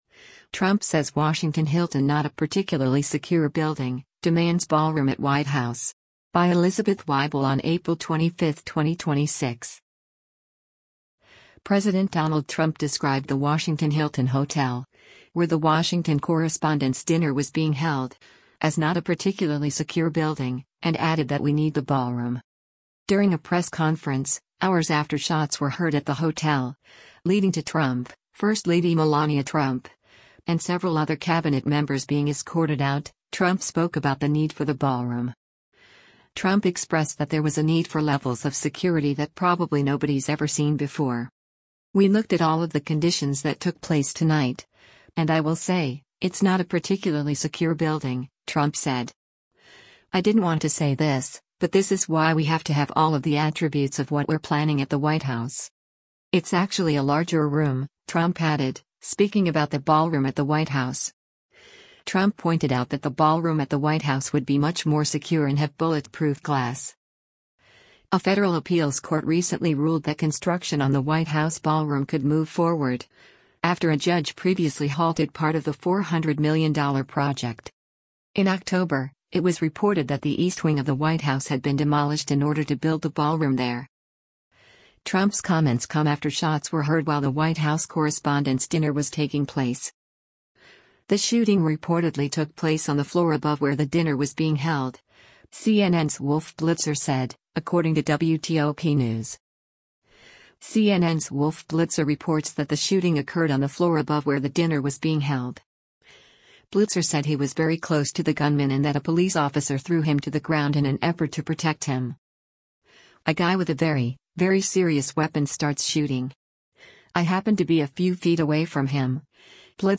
During a press conference, hours after shots were heard at the hotel – leading to Trump, first lady Melania Trump, and several other Cabinet members being escorted out – Trump spoke about the need for the ballroom.